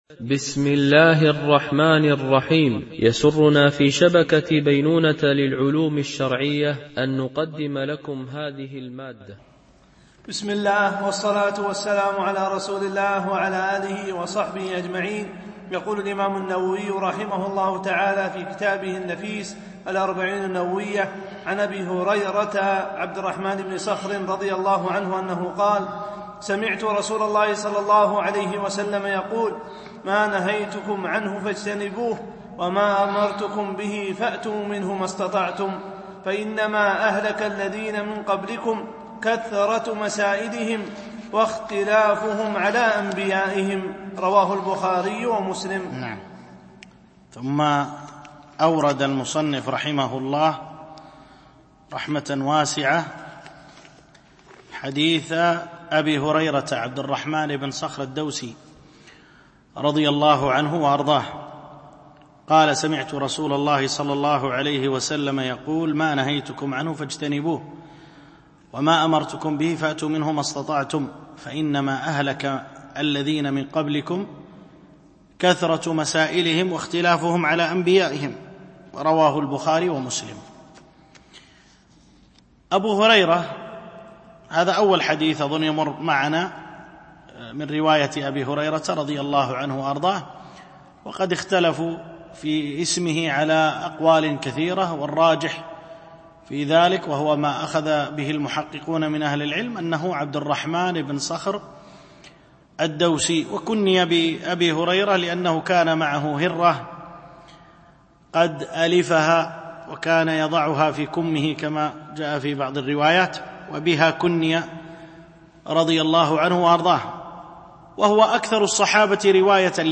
شرح الأربعين النووية - الدرس 6 (الحديث 9-10)
MP3 Mono 22kHz 32Kbps (CBR)